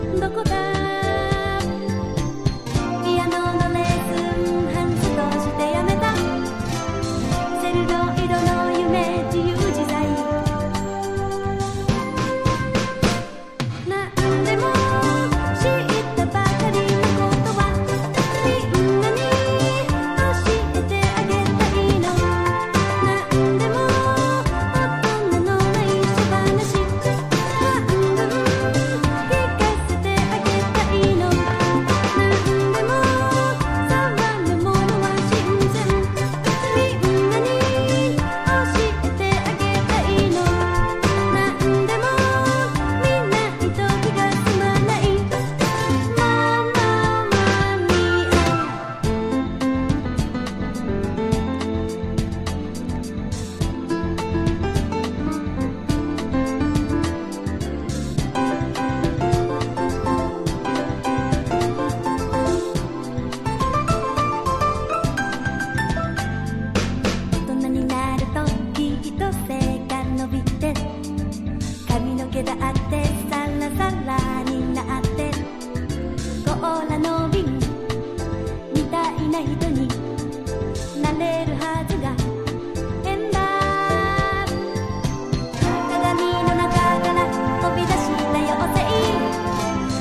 絶妙な歌謡曲感が◎なライトメロウ・ディスコ